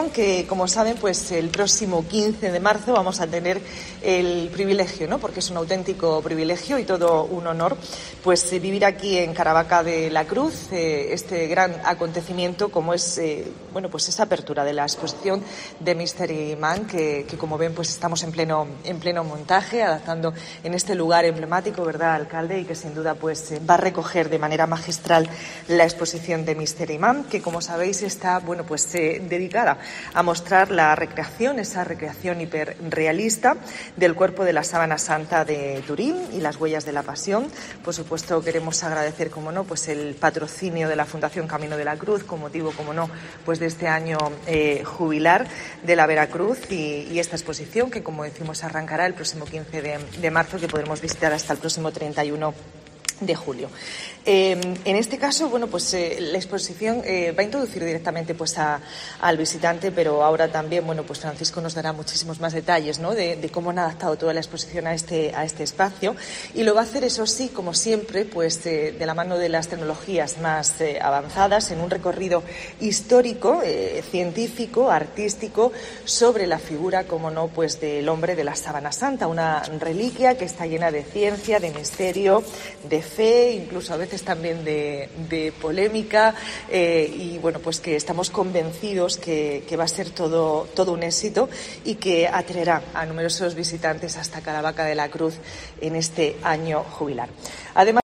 Carmen Conesa, consejera de Turismo, Cultura, Juventud y Deportes